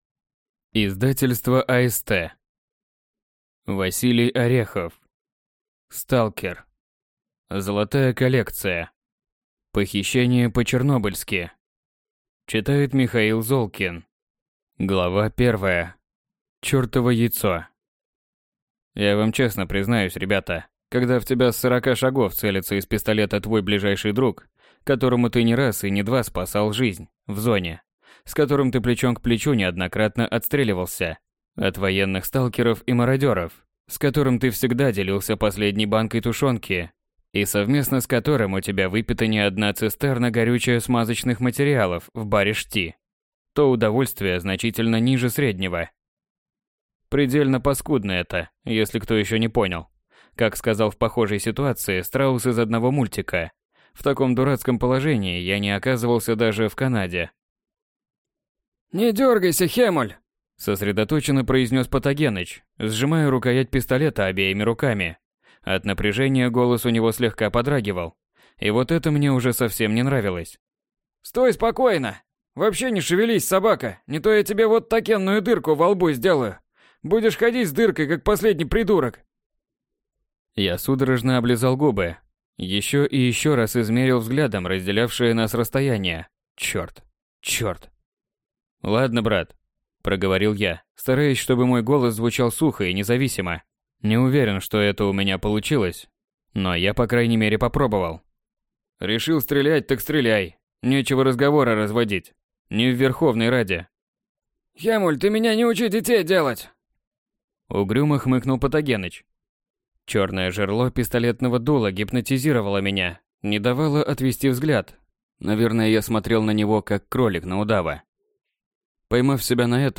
Аудиокнига Золотая коллекция. Похищение по-чернобыльски | Библиотека аудиокниг